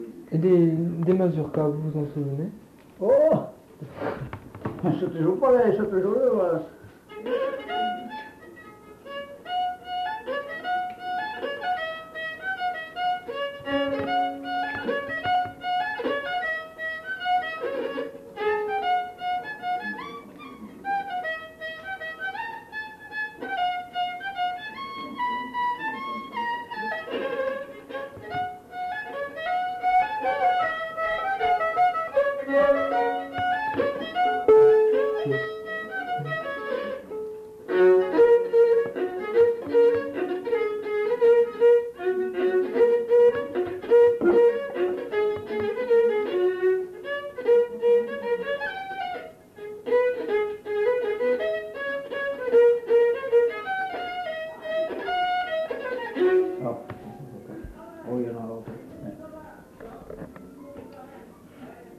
Lieu : Saint-Michel-de-Castelnau
Genre : morceau instrumental
Instrument de musique : violon
Danse : mazurka